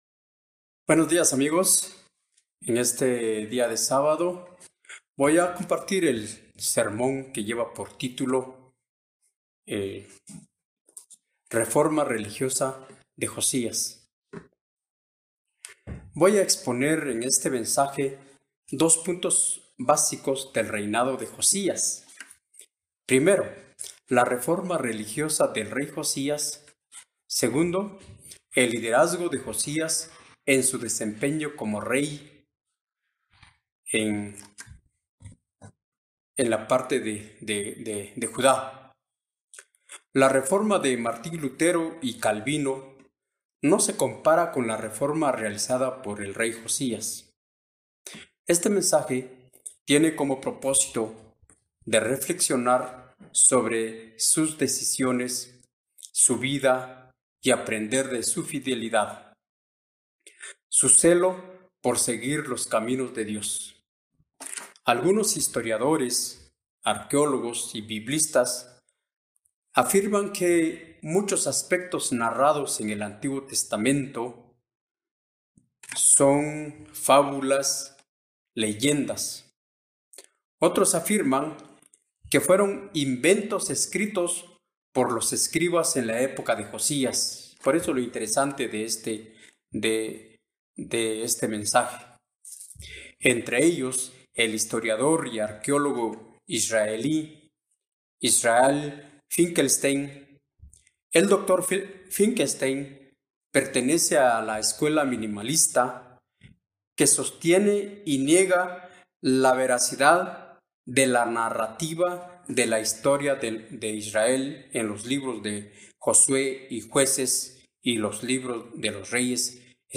Mensaje entregado el 19 de diciembre de 2020.